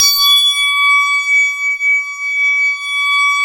SYN ANALOG#.wav